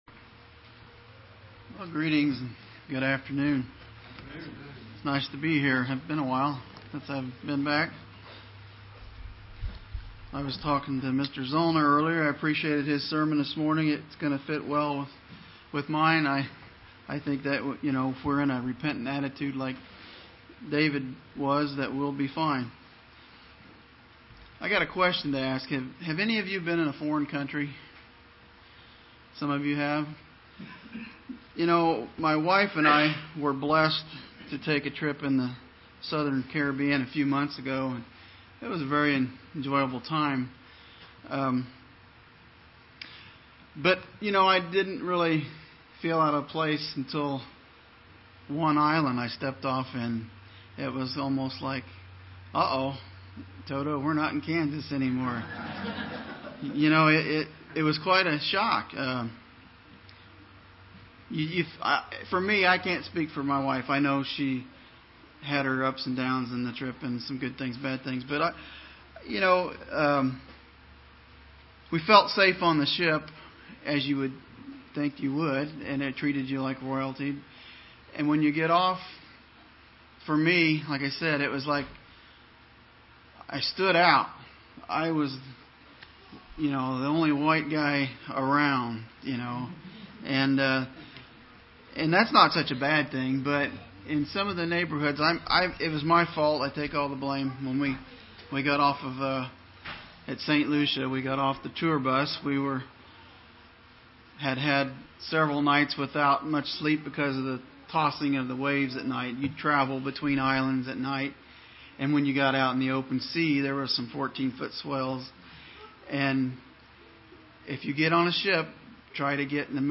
Given in Terre Haute, IN
UCG Sermon Studying the bible?